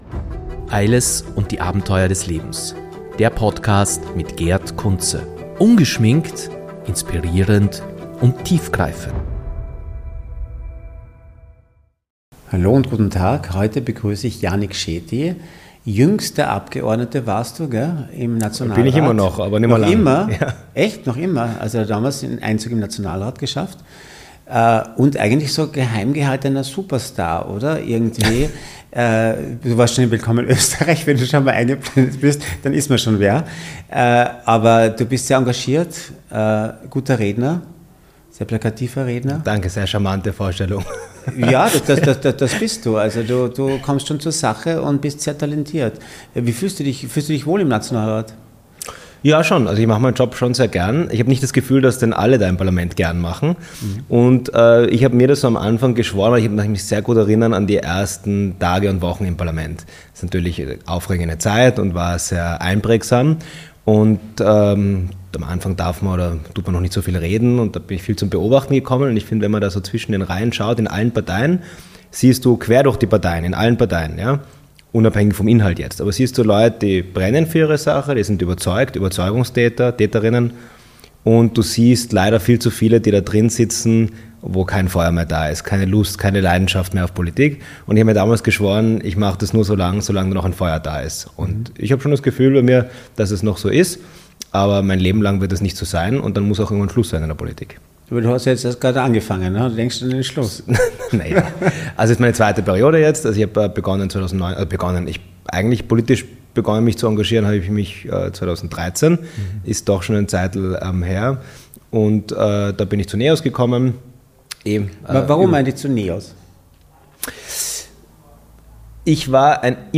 Yannick Shetty teilt seine Erfahrungen und Einblicke in die Politik, seine ersten Schritte im Parlament und die Herausforderungen, denen er als junger Politiker gegenübersteht. Er spricht offen über die Dynamik im Nationalrat, die Frustrationen der Oppositionsarbeit und seine Vision für eine inklusive und zukunftsorientierte Politik. Außerdem diskutiert er über aktuelle Themen wie die Integration, die Rolle von NEOS in der politischen Landschaft und seine persönlichen Ansichten zu Liberalismus und Migration. Ein inspirierendes und tiefgreifendes Gespräch, das nicht nur Politikinteressierte fesseln wird.